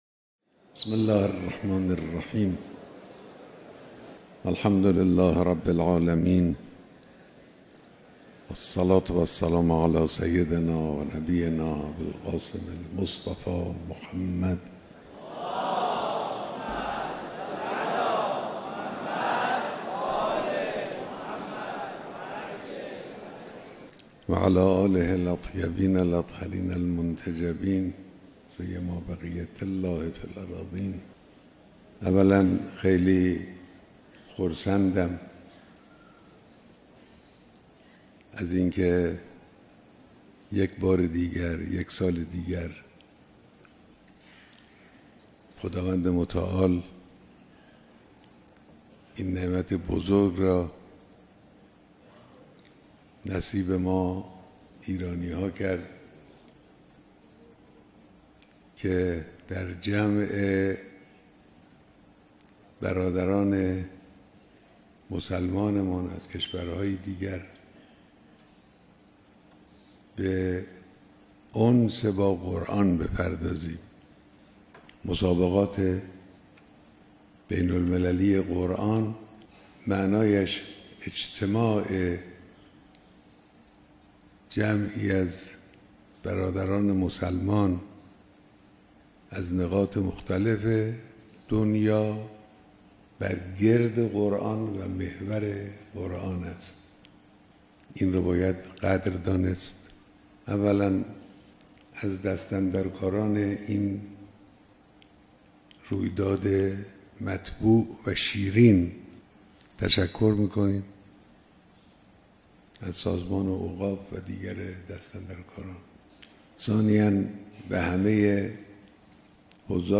بیانات در دیدار شرکت کنندگان در مسابقات بین‌المللی قرآن کریم